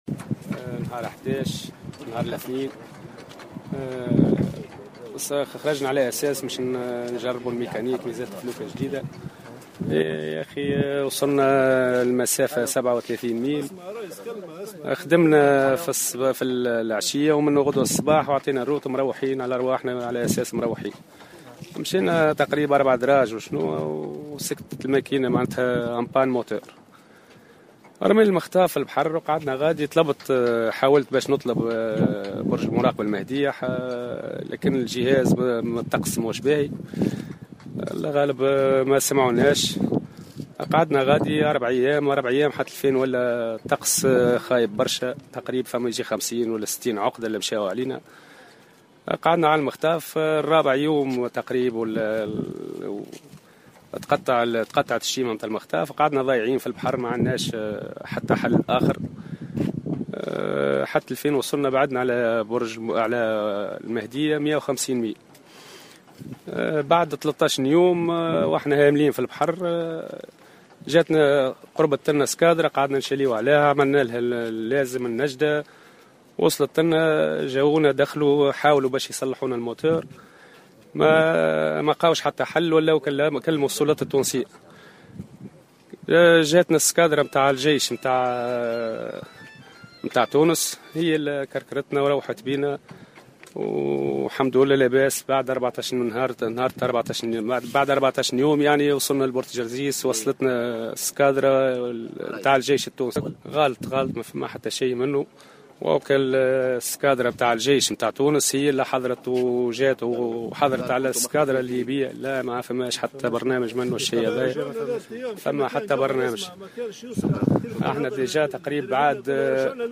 أحد البحارة